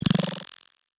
Hi Tech Alert 1.wav